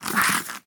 sounds / mob / fox / aggro3.ogg